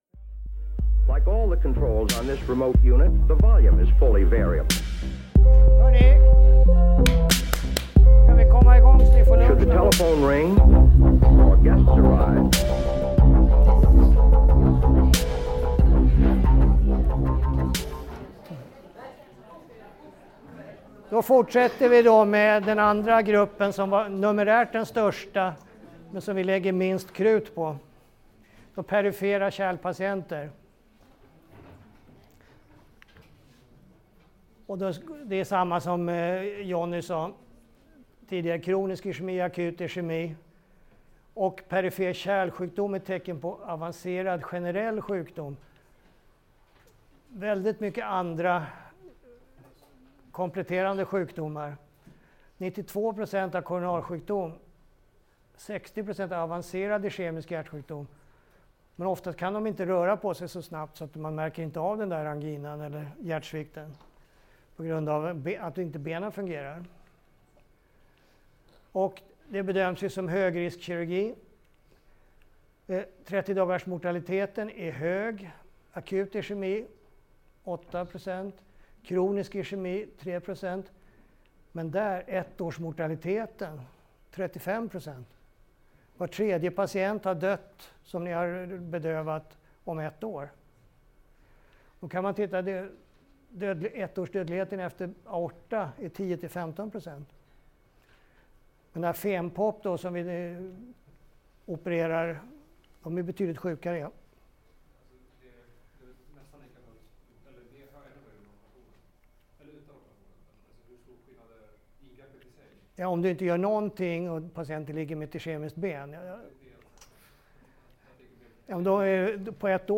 Inspelningen gjordes under ST-fredagen om anestesi vid kärlkirurgi på SöS, 5 februari 2019.